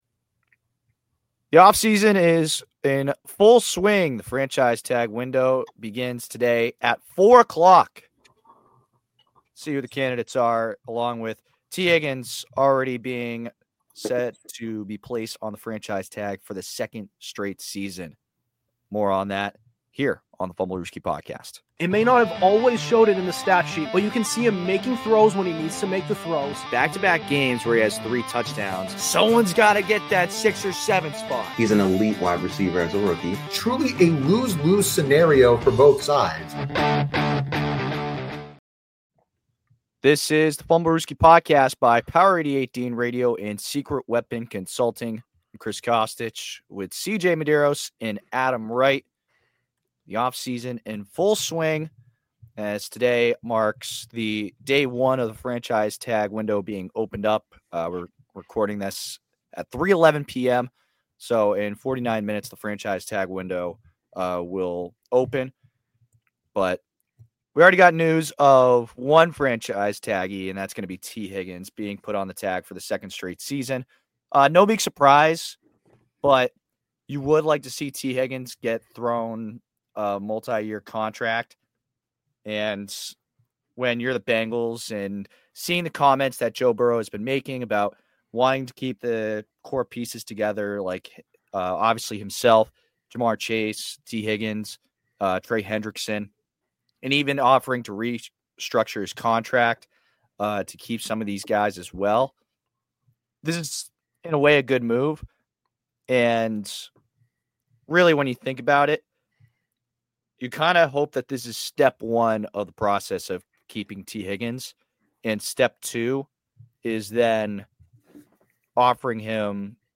An NFL podcast where we discuss all things pro-football-related, including recent news and hot takes. Hosted by four college guys